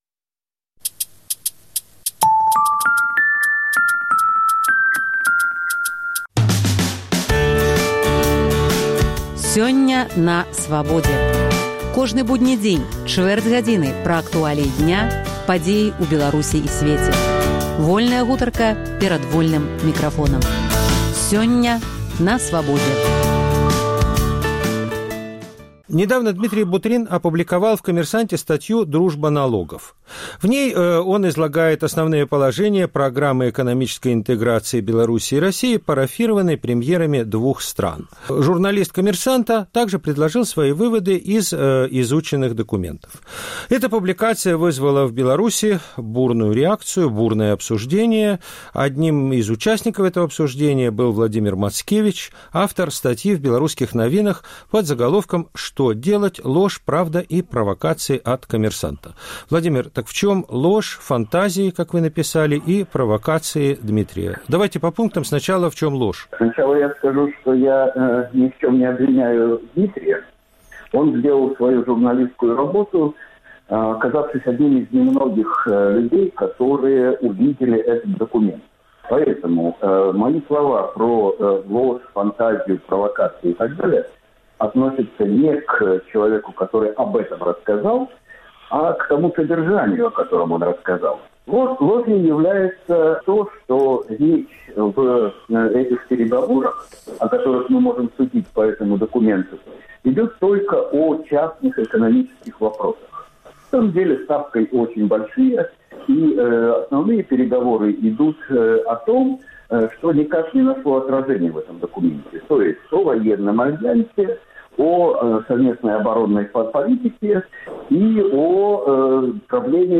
Сёньня на Свабодзе. Дыскусія